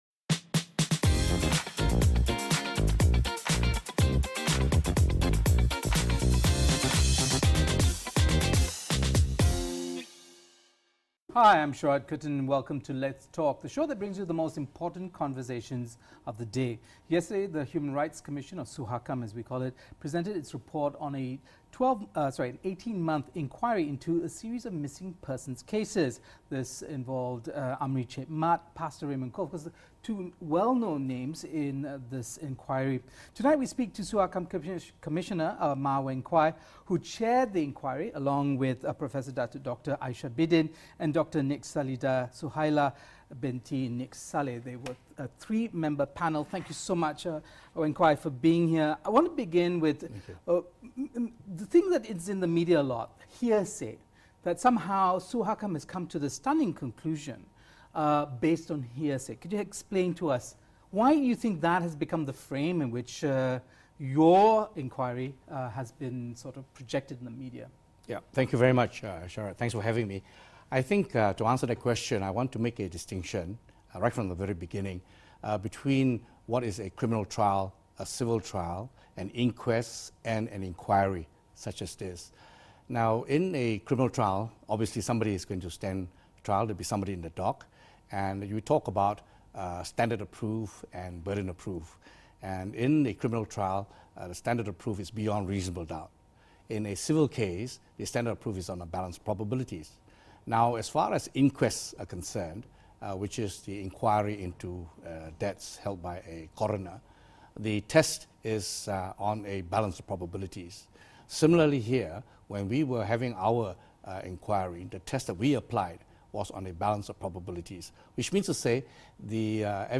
We speak to Suhakam Commissioner Datuk Mah Weng Kwai who chaired the Inquiry along with Professor Aishah Bidin and Dr Nik Salida Suhaila.